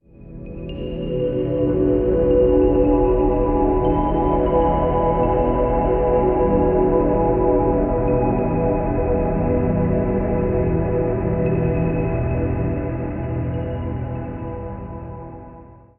Category 🎵 Relaxation
pad processed relax soundscape space sound effect free sound royalty free Relaxation